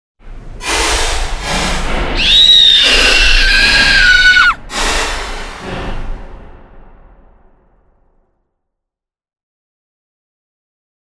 Sound details Duration: ~11 seconds Format: MP3 Tags: horror, scream, sound-effect